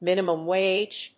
アクセントmínimum wáge